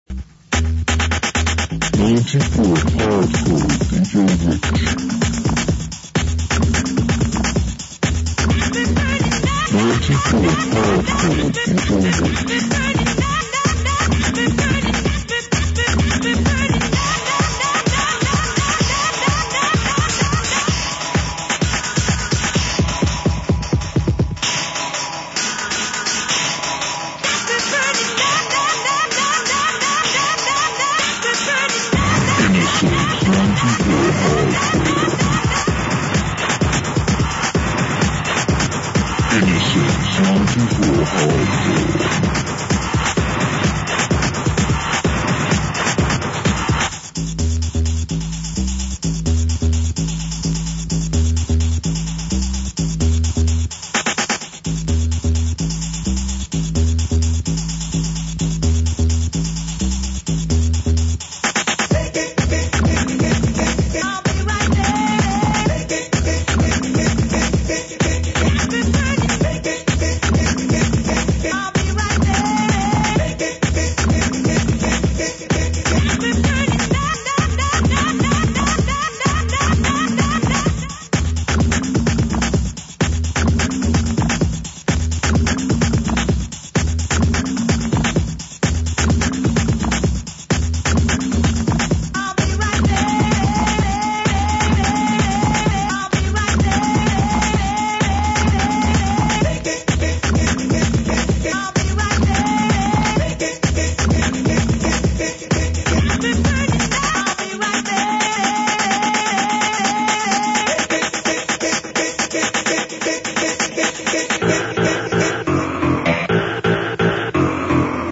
(quality a bit sus' on some tapes)
These tapes were recorded in Hammersmith, London and were some of the very first pirate radio stations I heard and recorded.
As with the Chillin' tapes, they had loads of adverts inbetween the music.